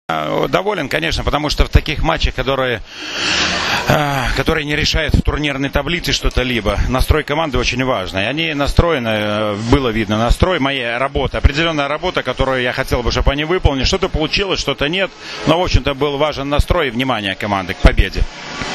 IZJAVA VLADIMIRA ALEKNA